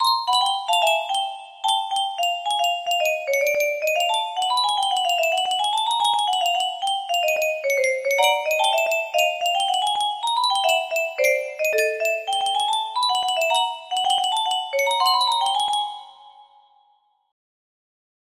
Osore or smth.. music box melody